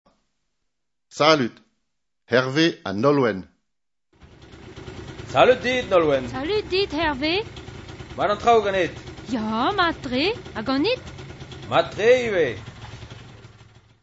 Divizioù
After listening to the dialogue a few times, act it out in pairs, using each other's names instead of those given in the dialogue.